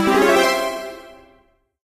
tutorial_enter_circle_01.ogg